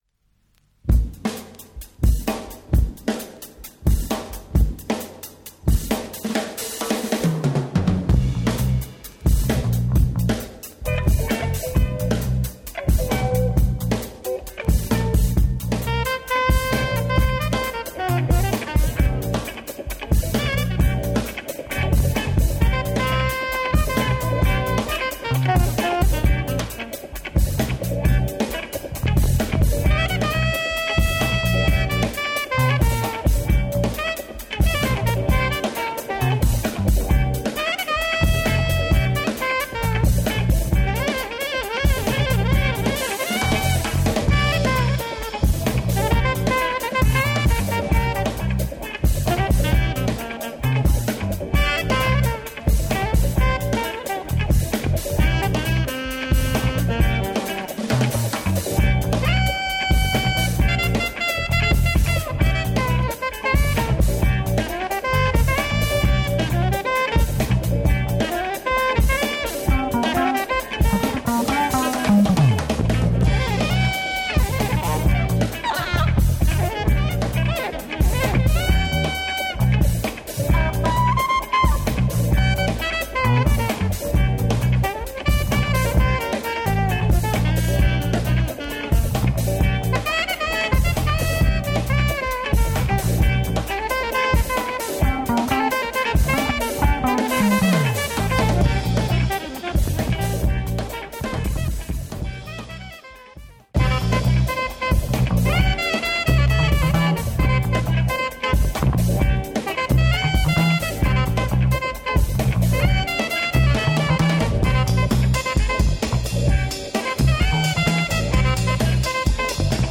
Heavy weight West Indian funk with breaks
Caribbean